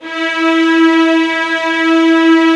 VIOLINS F#-L.wav